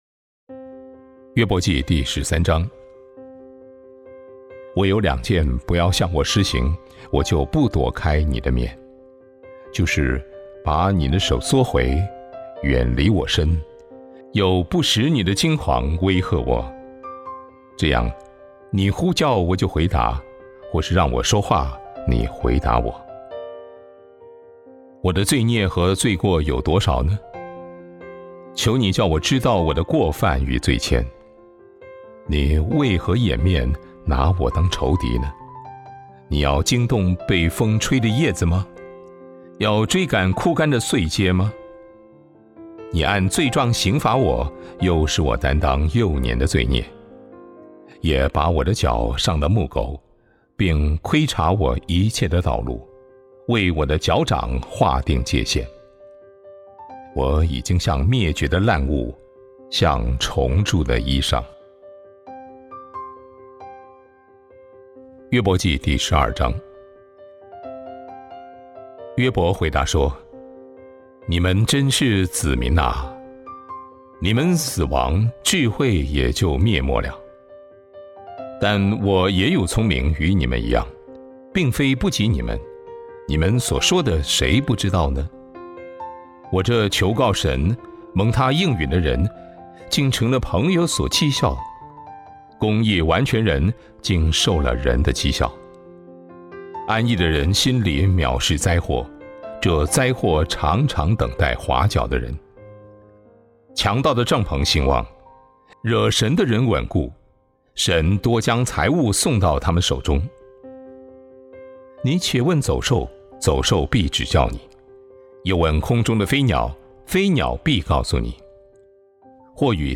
当上帝显得遥远时 | Devotional | Thomson Road Baptist Church